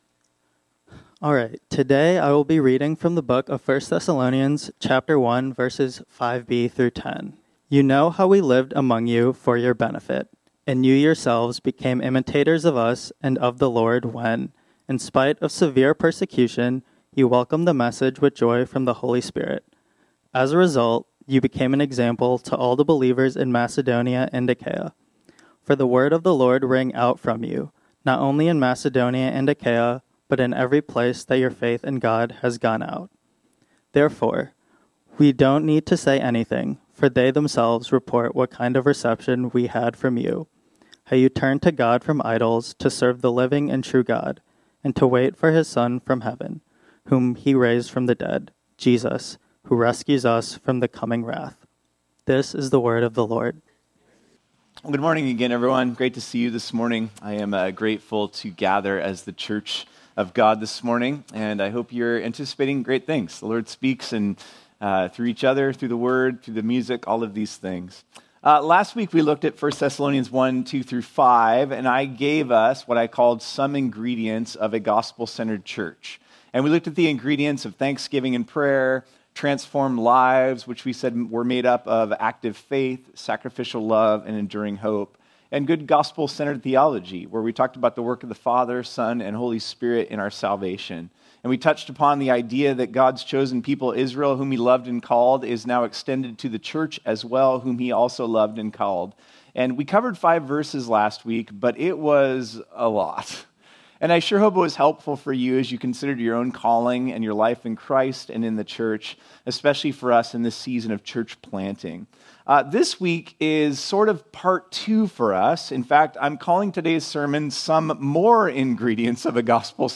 This sermon was originally preached on Sunday, June 15, 2025.